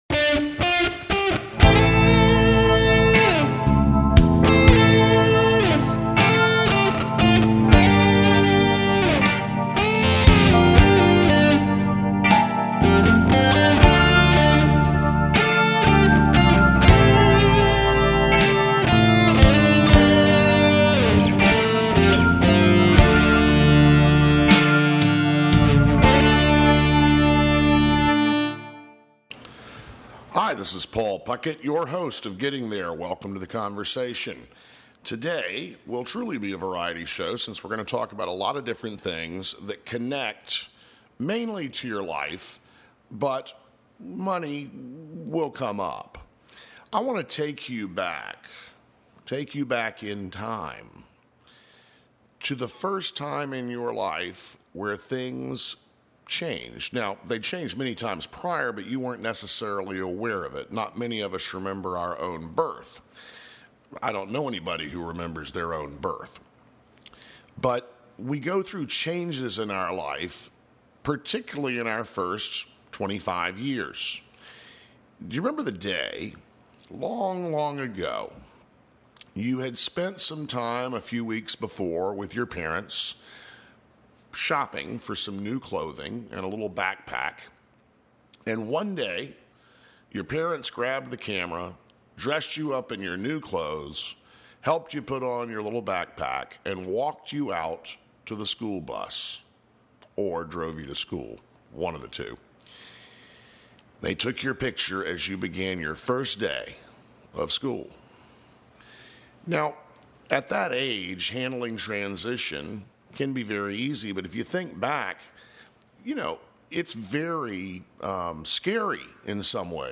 Talk Show
In addition to money issues, the conversation will include gardening, cooking, books, travel, and interviews with guests from the financial field as well as chefs, gardeners, authors, and spiritual leaders.